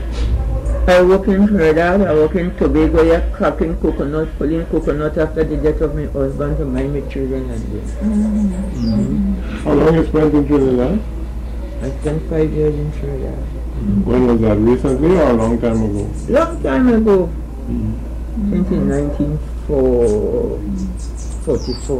Its aim is to document the linguistic history of Tobago. The names and bio-data of the persons interviewed are available.
19 audio cassettes
Oral Tradition, Cultural Heritage: Trinidad and Tobago, Linguistics, Villages: Tobago, Tobago